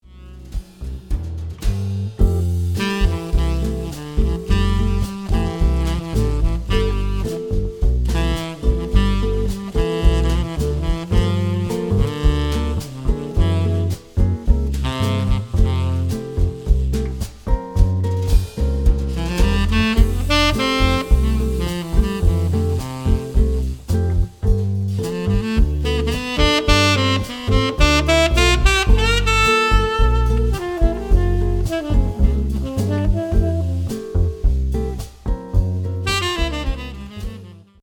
quartet.mp3